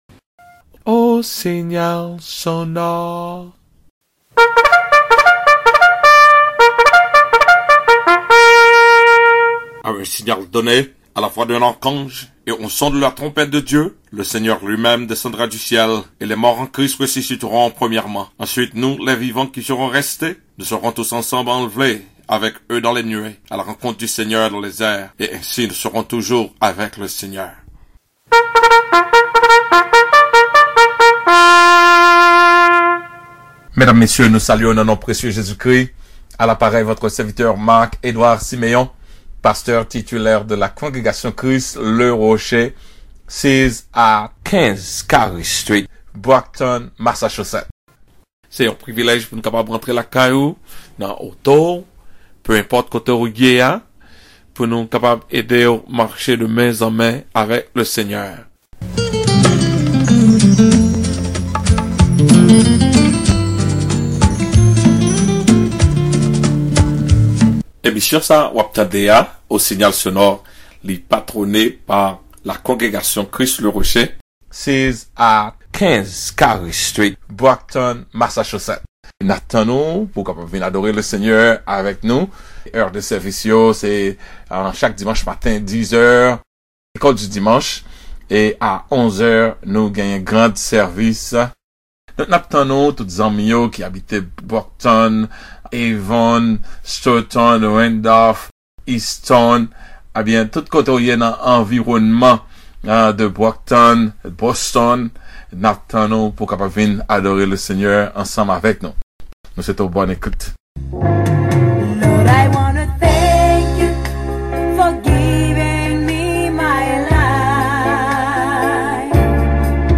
CLICK HERE TO DOWNLOAD THE SERMON: THE FEAR OVER THE NEXT GENERATION (1)